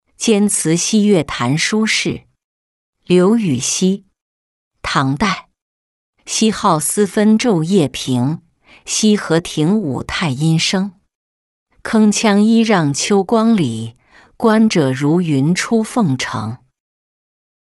监祠夕月坛书事-音频朗读